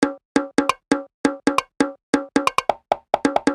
Lite Conga Loop 1 135bpm.wav